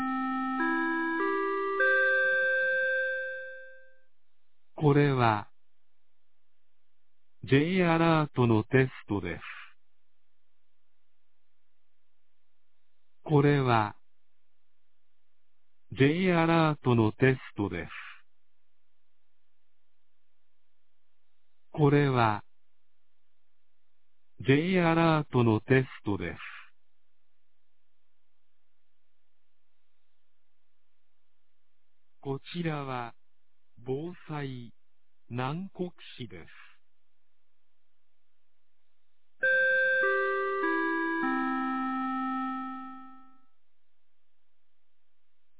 2022年11月16日 11時01分に、南国市より放送がありました。